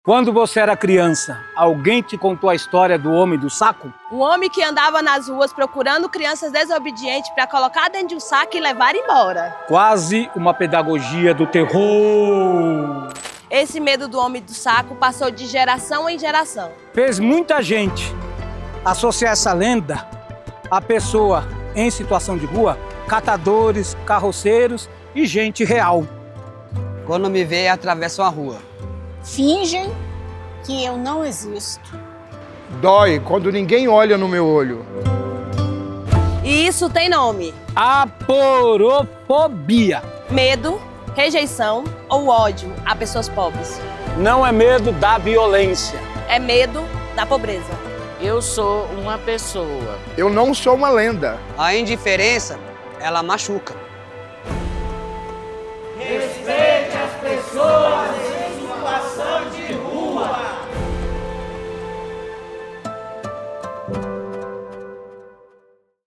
Spot - Indiferença Machuca - Respeite as Pessoas em Situação de Rua